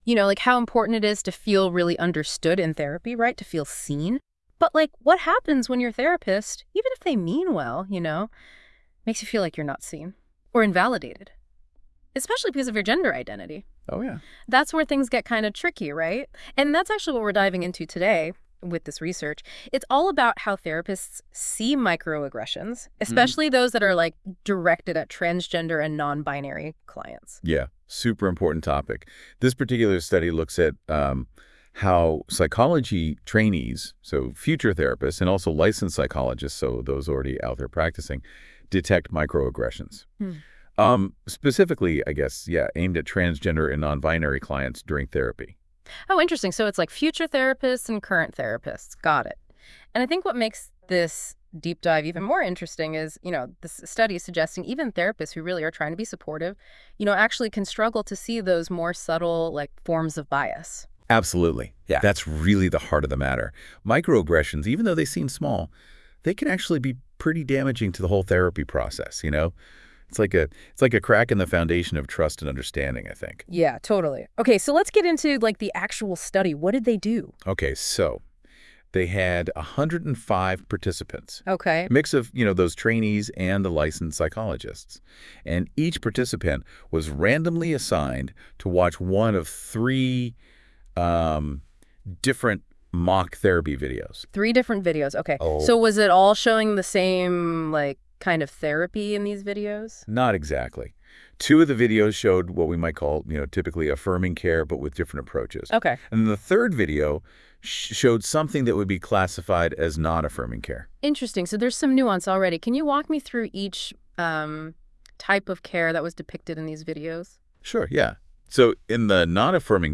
This podcast was generated by Notebook LM and reviewed by our team, please listen with discretion. Transgender and nonbinary (TGNB) people report experiencing discrimination from therapists.